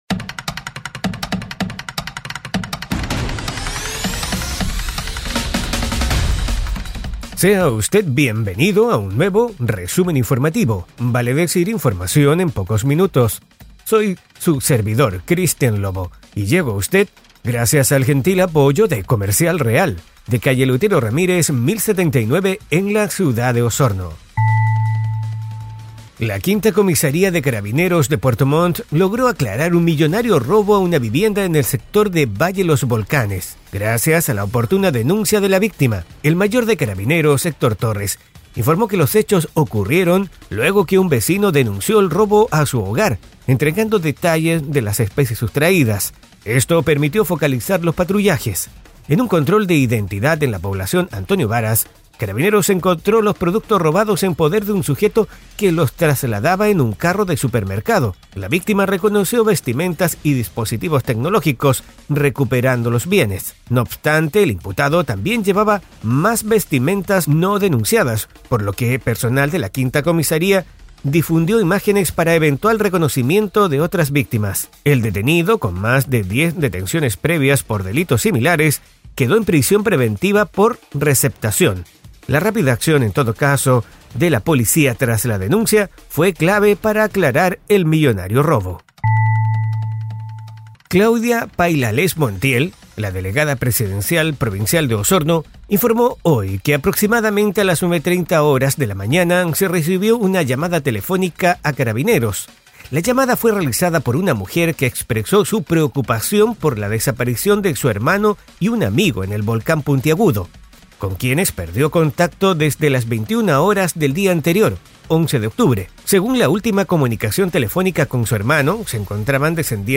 Este audio podcast te trae un resumen rápido y conciso de una decena de noticias enfocadas en la Región de Los Lagos.